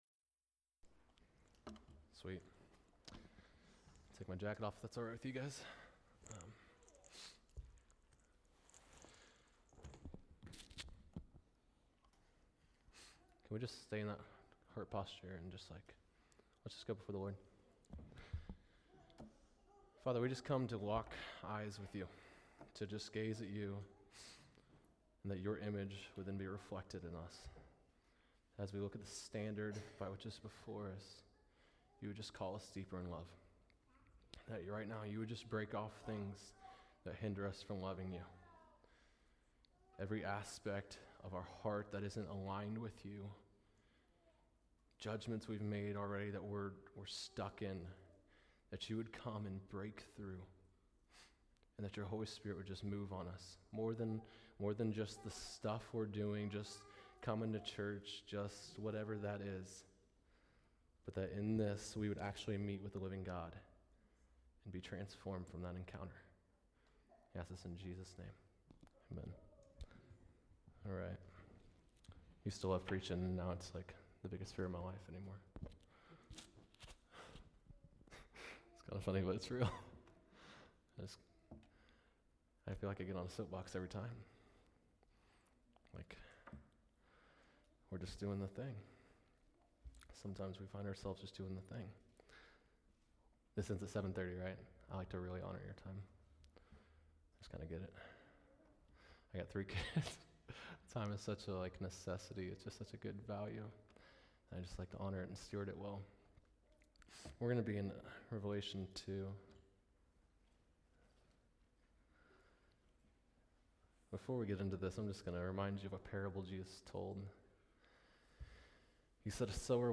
Sermons Archive – Immanuel Baptist Church